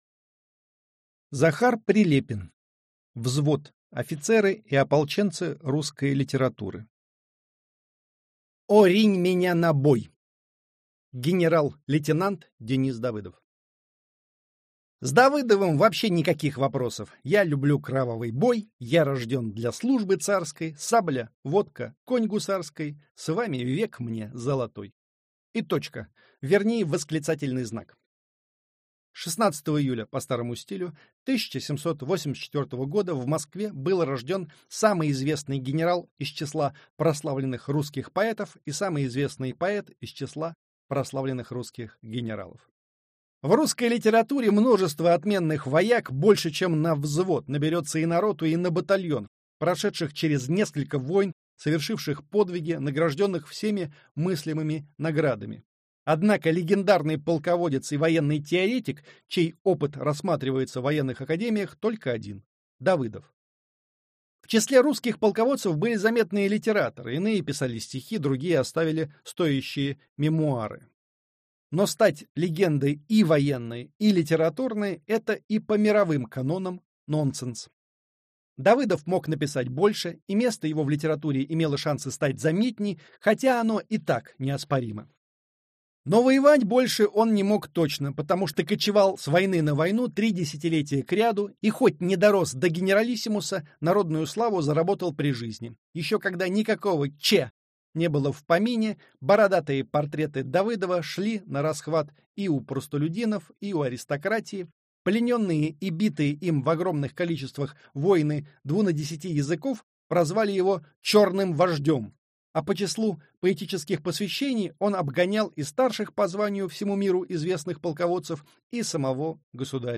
Аудиокнига Взвод. Офицеры и ополченцы русской литературы. Генерал-лейтенант Денис Давыдов | Библиотека аудиокниг